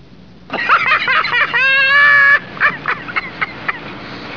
drunklaf.wav